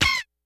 perc_18.wav